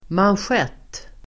Ladda ner uttalet
manschett.mp3